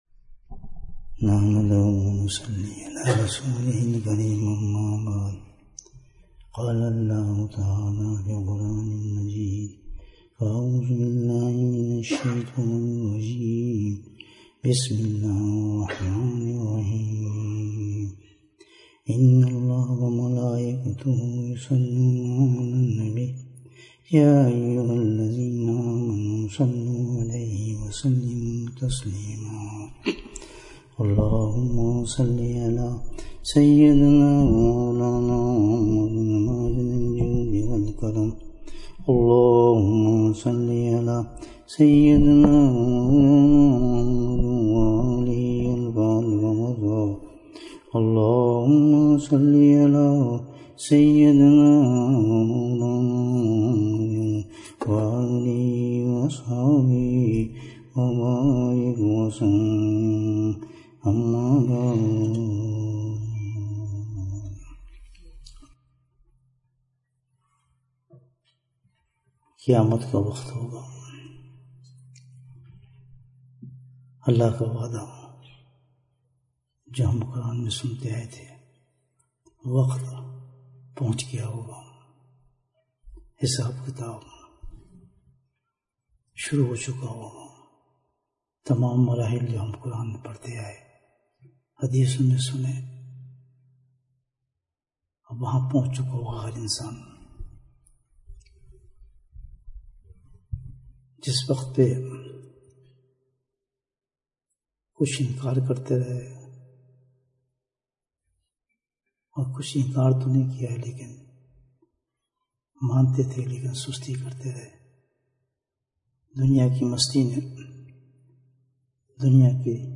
Bayan, 72 minutes11th February, 2023